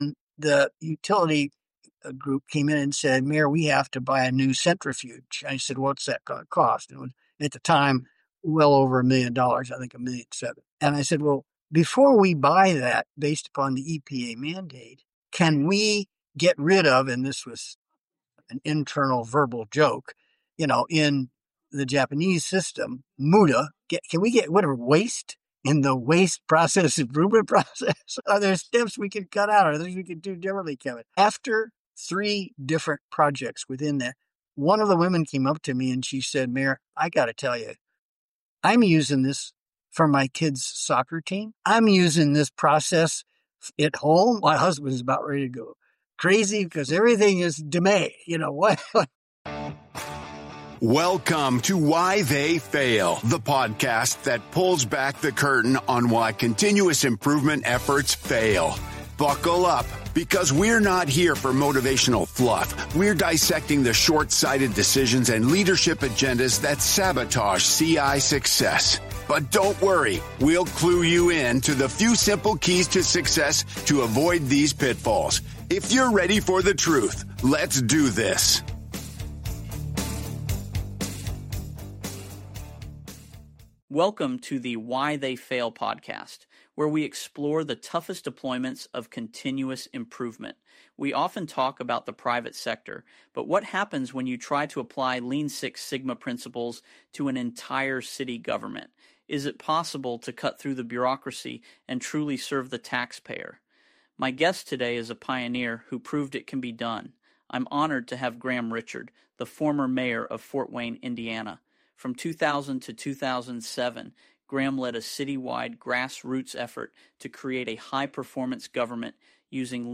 Insights from former Mayor Graham Richard.